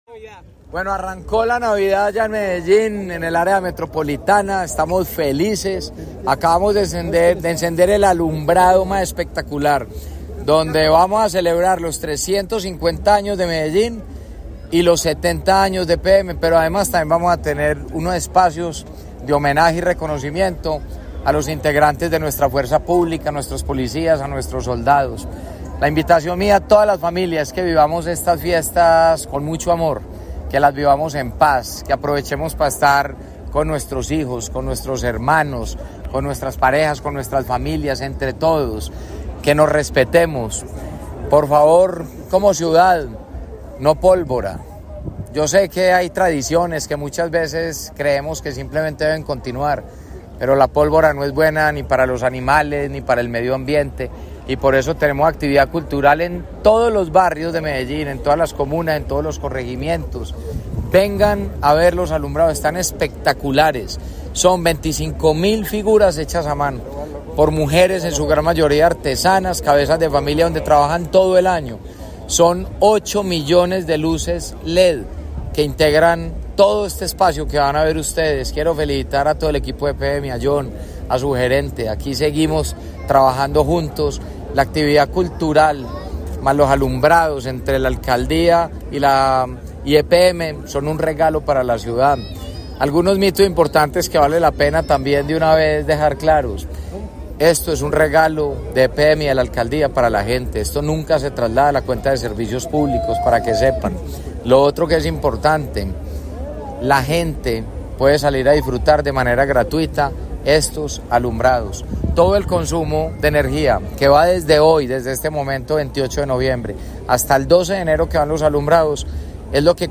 Declaraciones del alcalde de Medellín, Federico Gutiérrez
Declaraciones-del-alcalde-de-Medellin-Federico-Gutierrez-01-3.mp3